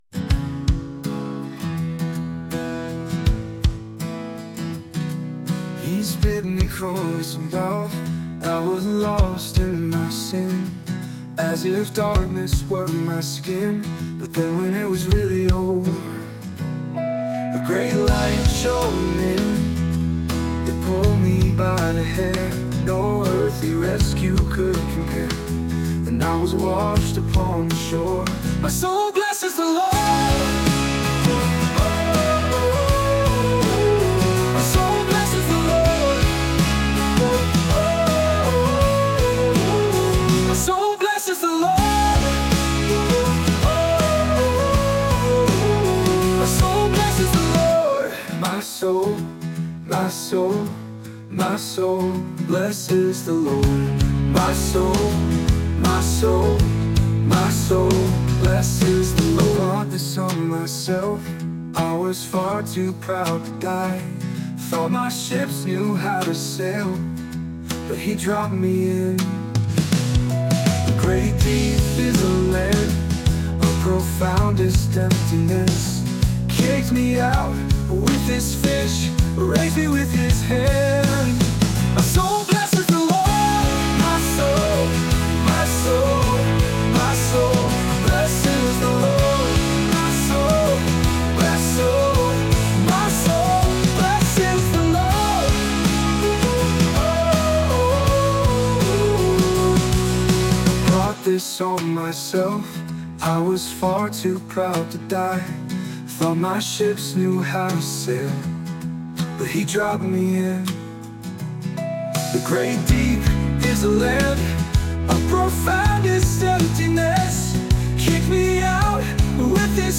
Mir fehlt da ein bisschen Tiefe 🙂 Interessanter wird es wenn man den Text auf Englisch schreiben lässt:
(Liedtext von Suno)